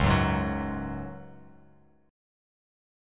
C#sus4 chord